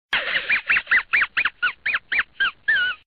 dog_laugh.ogg